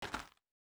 Shoe Step Gravel Medium A.wav